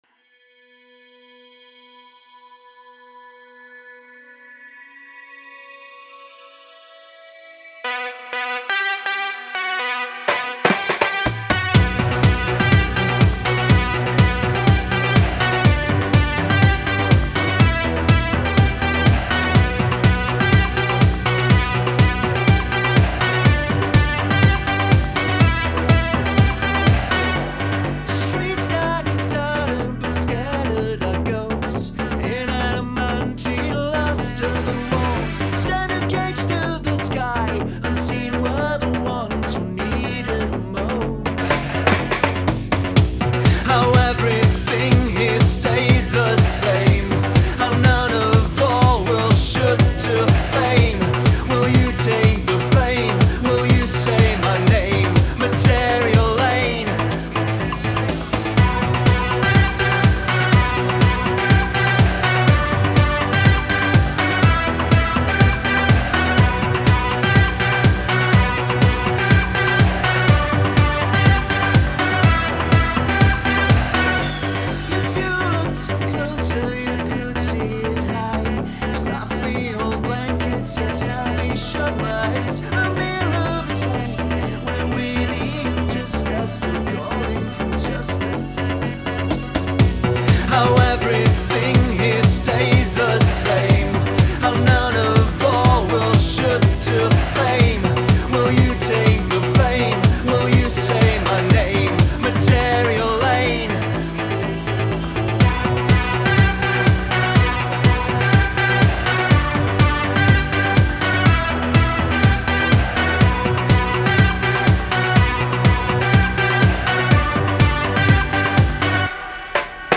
Vom pseudo-80er-Klang hab ich mich dann doch verabschiedet und das Ding beschleunigt. Klingt mir noch ein bissi pappmaschéig.
Die neue Fassung von dem Lied klingt voll grauenhaft, wie ich mit Abstand und vor allem mit Lautsprecher und nicht Kopfhörer merke.